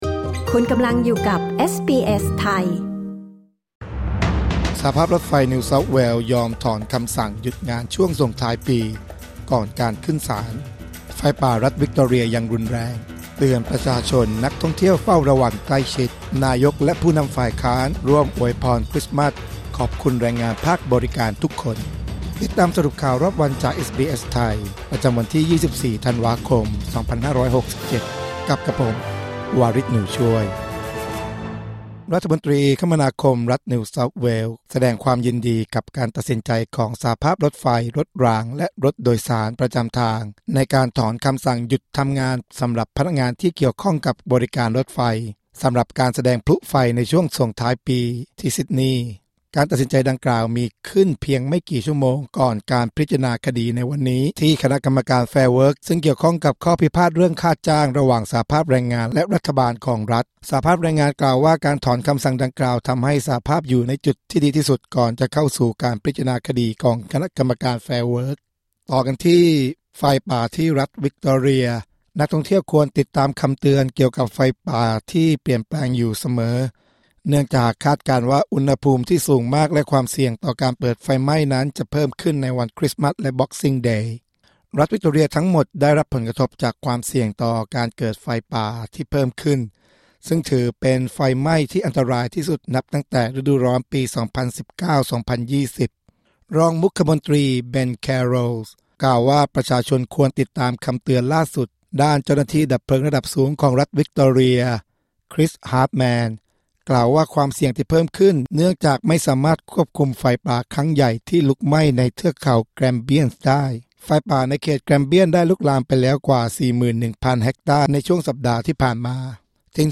สรุปข่าวรอบวัน 24 ธันวาคม 2567